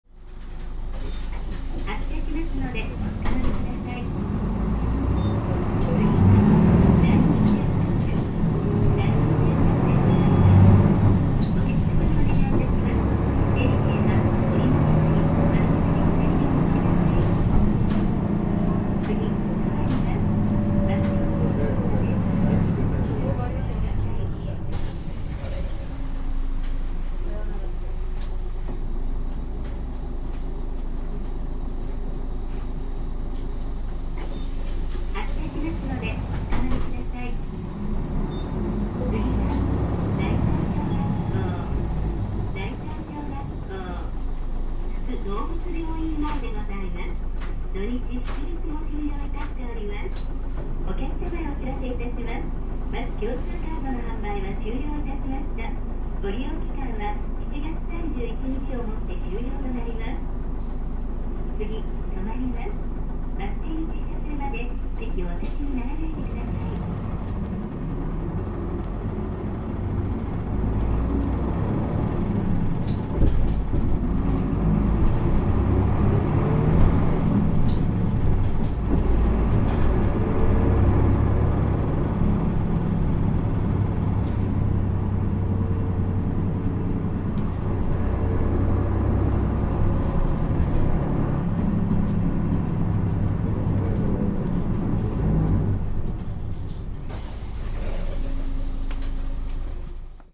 全国路線バス走行音立川バス
備考：DD5速，フィンガーシフト
PKG規制の車両はPJ規制の車両に比べて吹き上がりの音が力強い印象があります。バス共通カード終了の案内放送も収録。